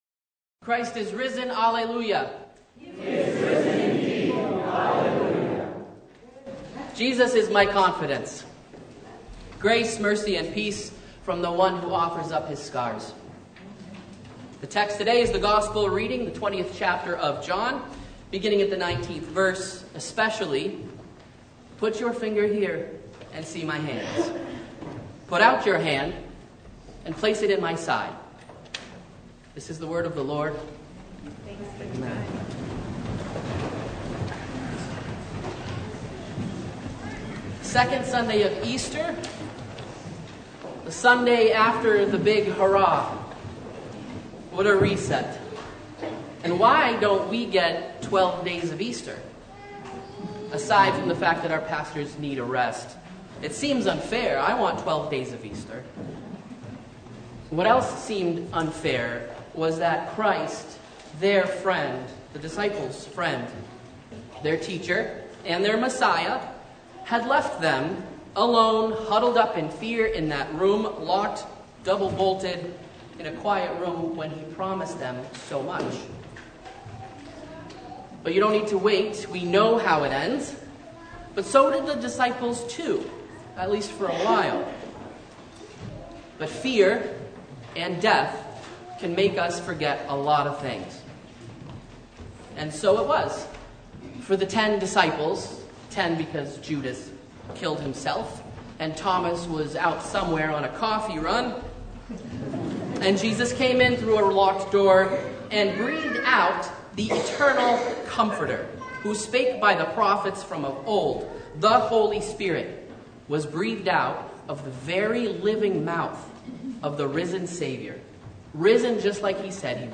Sermon Only « The Second Sunday of Easter